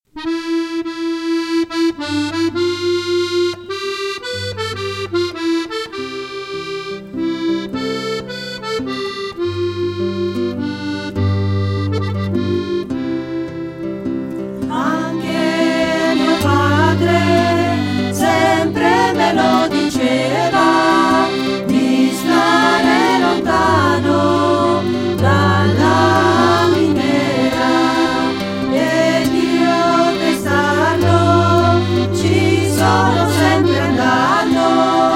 Taissine: cernitrici di minerale nelle miniere bergamasche [Gruppo folklorico]
Canto popolare dedicato alla tradizione mineraria; il minatore, i pericoli della miniera, la devozione a Santa Barbara